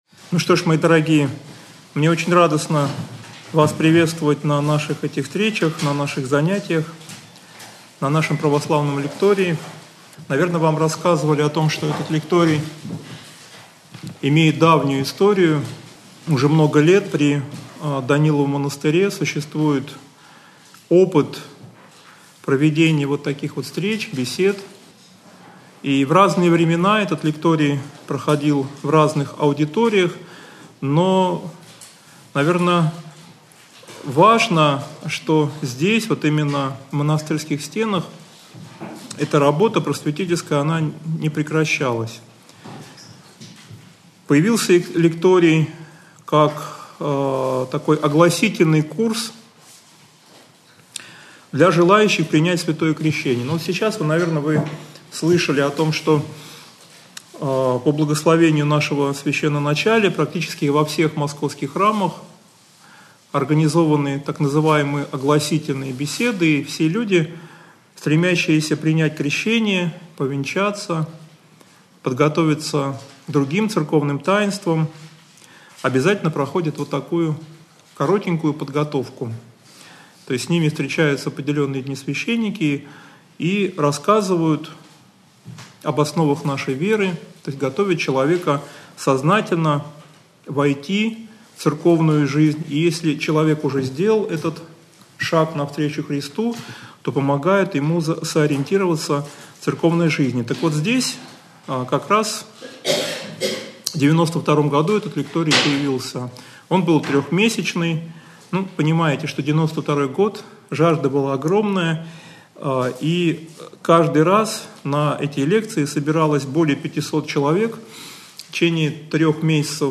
Аудиозапись лекции mp3
Общедоступный православный лекторий